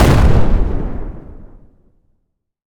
explosion_large_05.wav